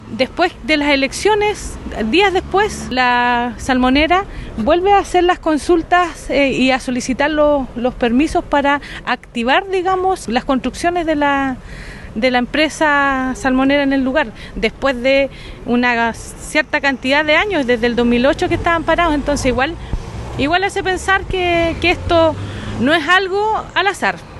La concejala Alejandra Martínez calificó como inadecuado que el alcalde no haya informado al Concejo Municipal y a la comunidad la reunión que sostuvo con la agencia.